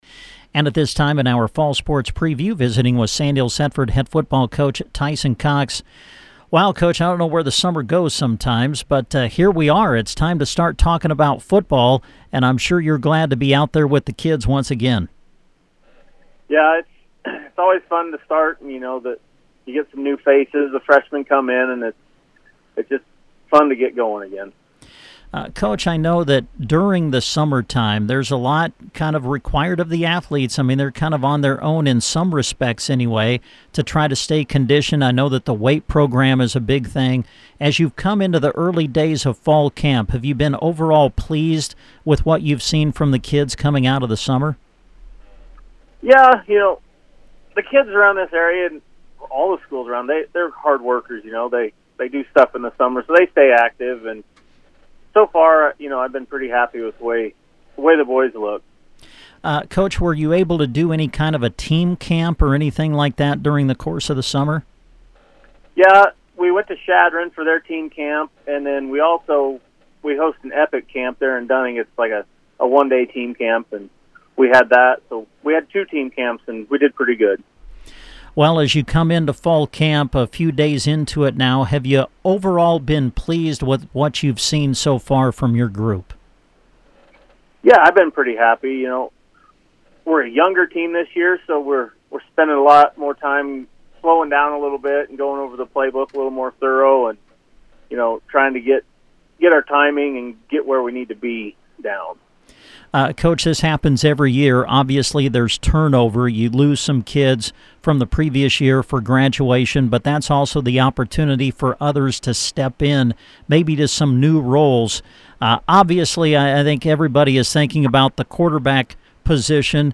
Sandhills/Thedford Football Preview / Interview